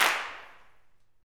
CLAPSUTC1.wav